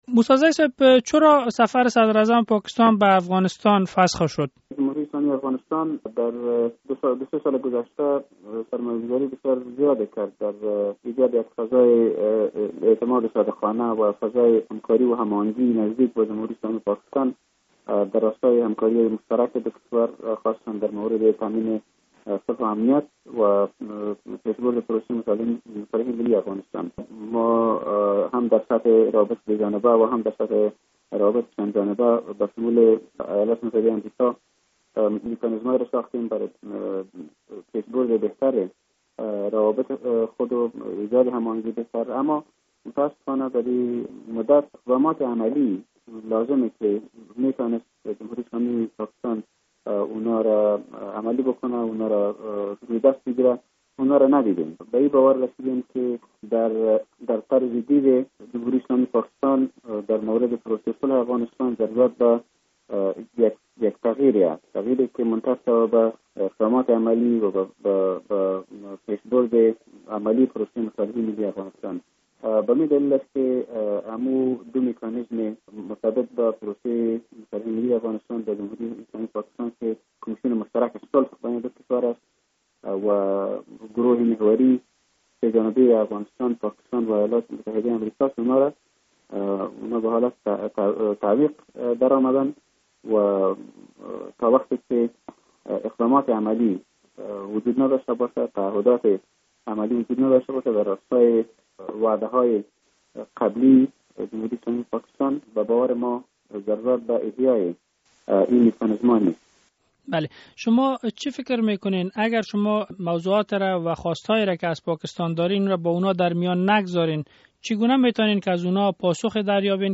مصاحبه در مورد لغو سفر صدراعظم پاکستان به افغانستان